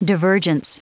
Prononciation du mot divergence en anglais (fichier audio)
Prononciation du mot : divergence